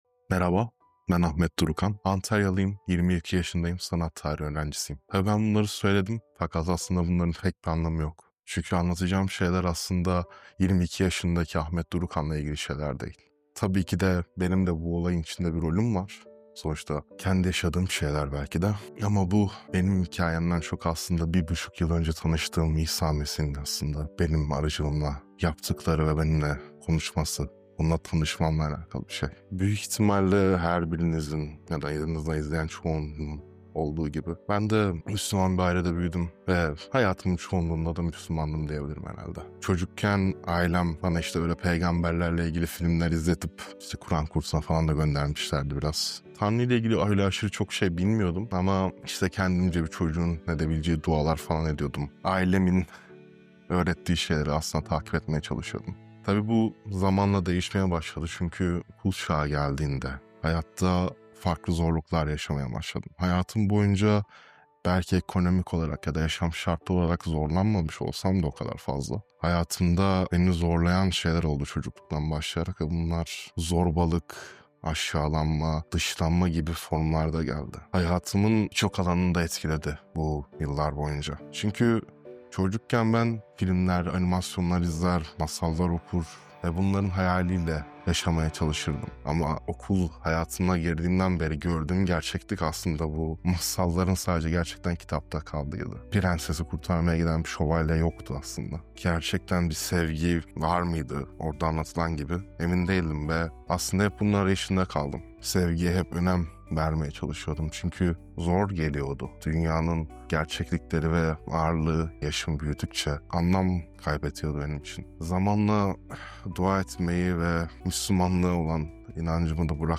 Neden ve Nasıl Hristiyan Oldum? TÜRK Hristiyan Anlatıyor!